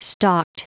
Prononciation du mot stalked en anglais (fichier audio)
Prononciation du mot : stalked